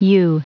Prononciation du mot ewe en anglais (fichier audio)
Prononciation du mot : ewe